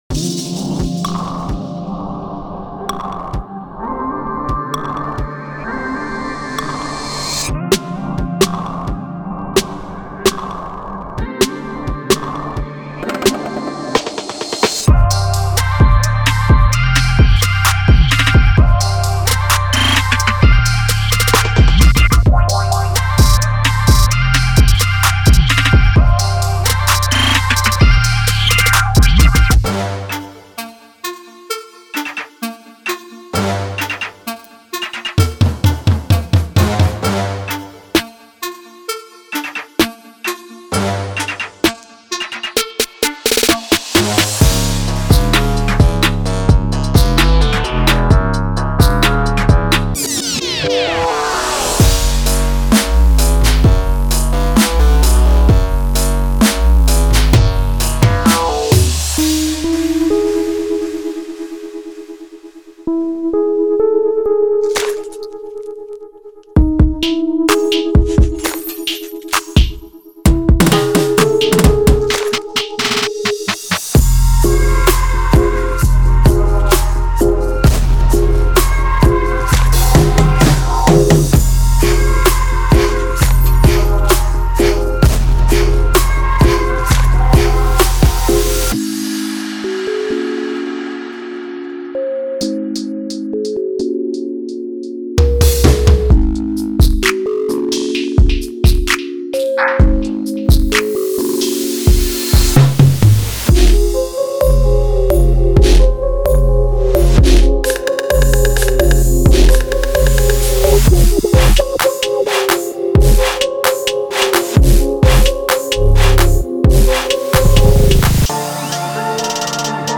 云陷阱样本包。
用于Cloud Trap，R’n’B，Smoky Hip Hop，Chill Hop，Chill Trap等。
●完全混合和掌握